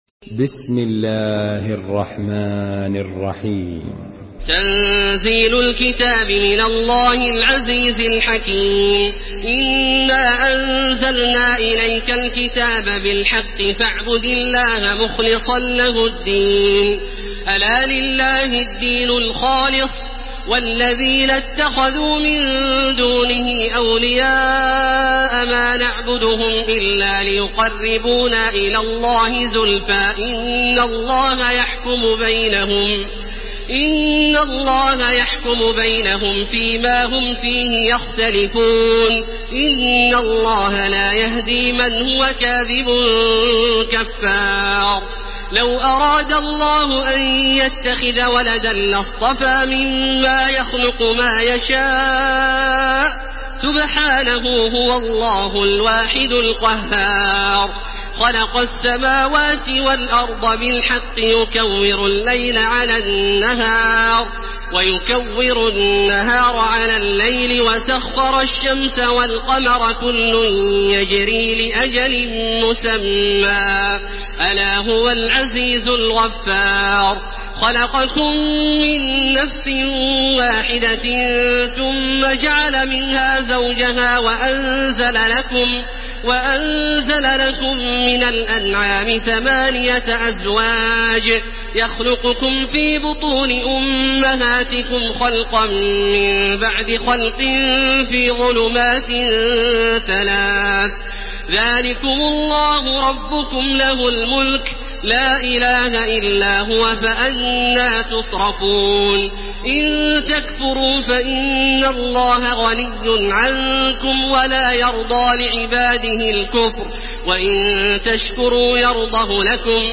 Surah Az-zumar MP3 by Makkah Taraweeh 1431 in Hafs An Asim narration.
Murattal